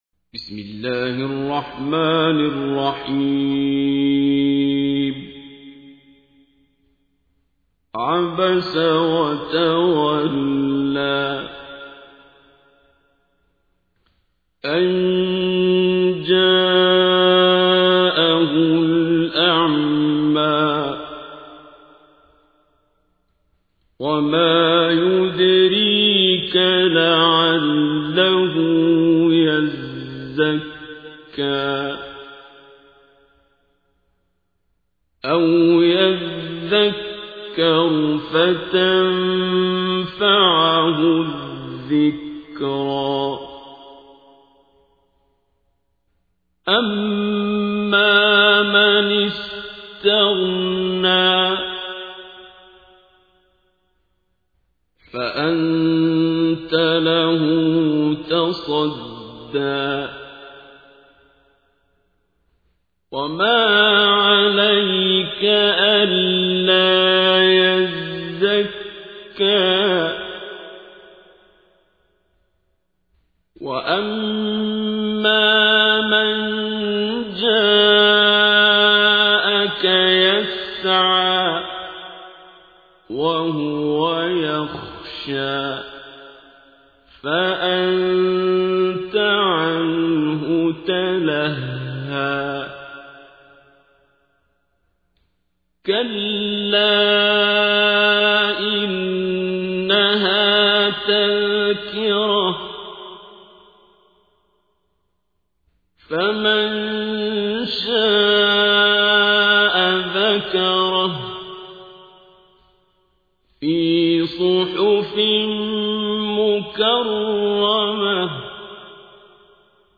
تحميل : 80. سورة عبس / القارئ عبد الباسط عبد الصمد / القرآن الكريم / موقع يا حسين